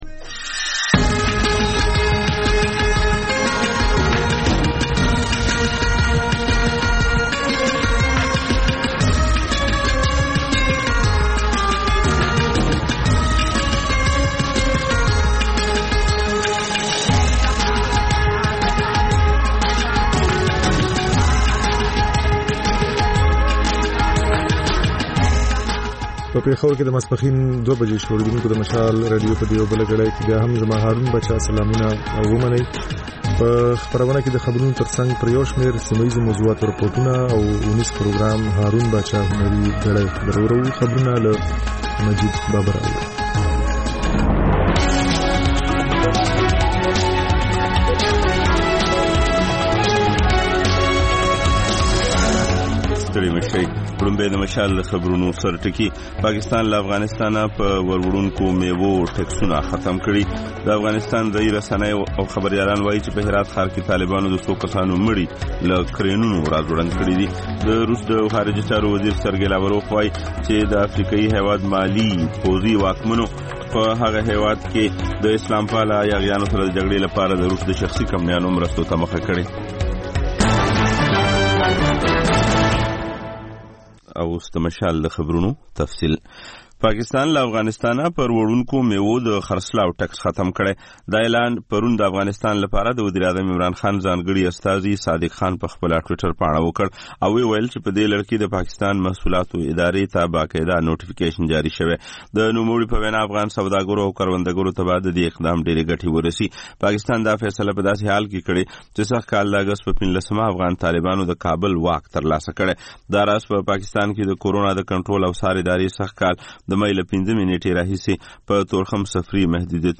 د مشال راډیو دویمه ماسپښینۍ خپرونه. په دې خپرونه کې لومړی خبرونه او بیا ځانګړې خپرونه خپرېږي.